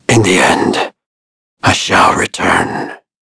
Shakmeh-Vox_Dead_c.wav